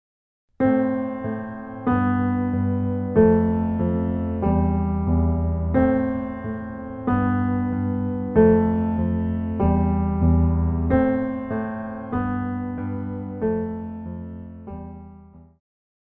古典,流行
钢琴
演奏曲
世界音乐
仅伴奏
没有主奏
没有节拍器